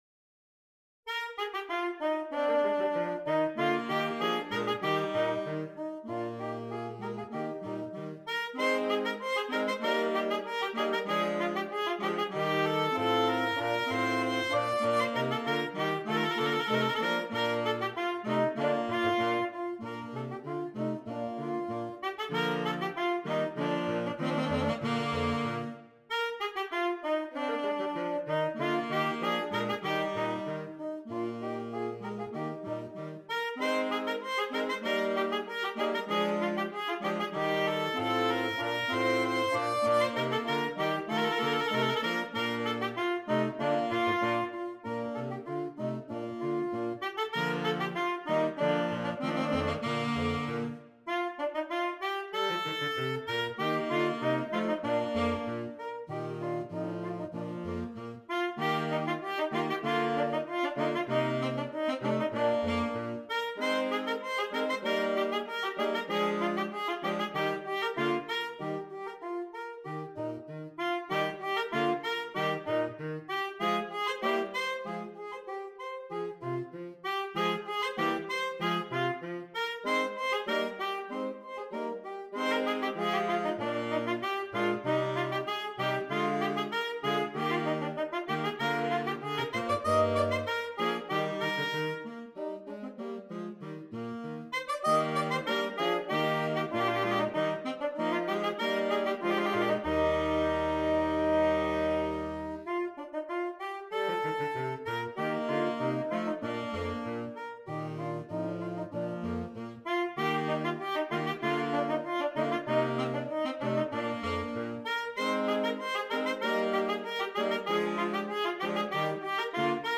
Gattung: Für Saxophonquartett